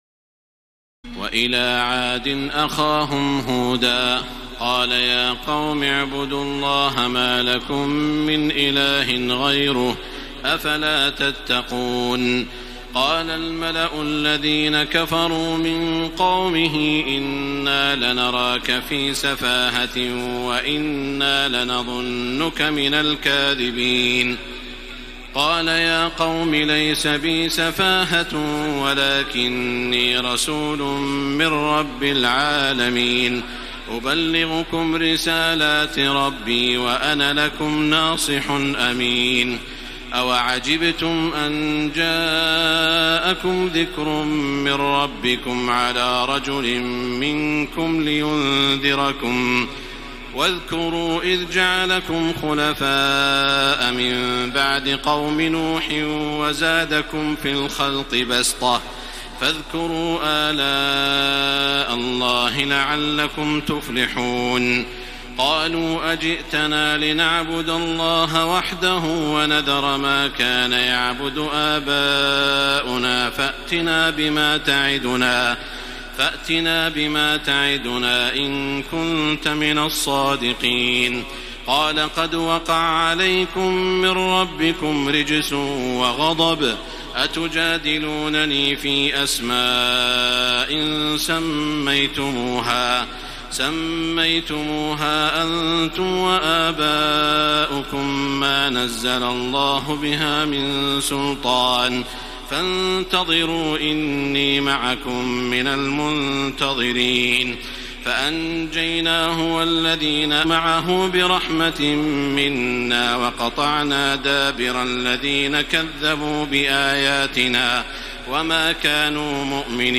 تراويح الليلة الثامنة رمضان 1433هـ من سورة الأعراف (65-162) Taraweeh 8 st night Ramadan 1433H from Surah Al-A’raf > تراويح الحرم المكي عام 1433 🕋 > التراويح - تلاوات الحرمين